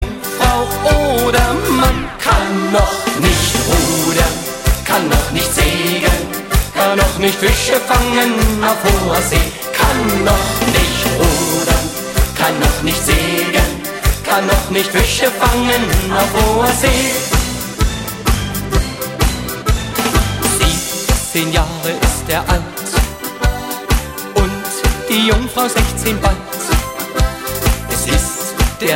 Besetzung: Blasorchester
Tonart: B-, Es- und C-Dur
Stil: Polka Beat